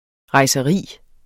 Udtale [ ʁɑjsʌˈʁiˀ ]